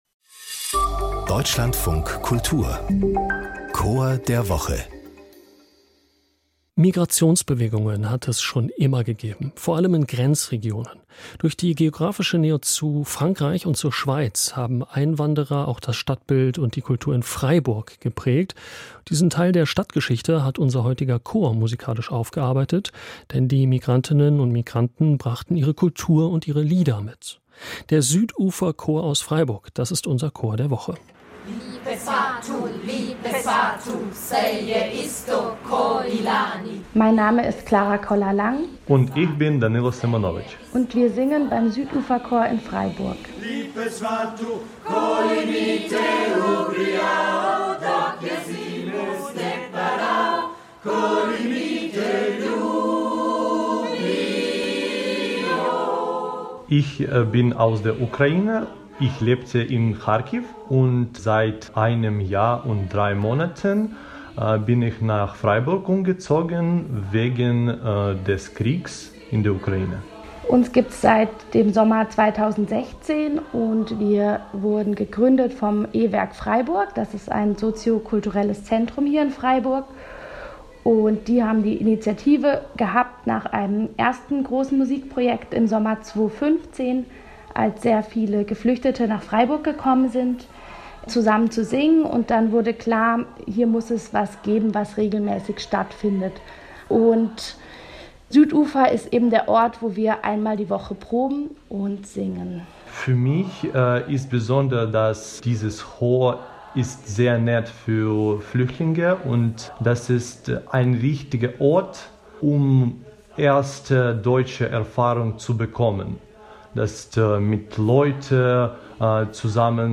Hier stellen wir Ihnen jede Woche einen Chor vor.